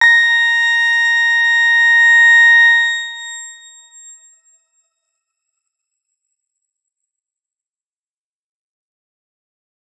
X_Grain-A#5-mf.wav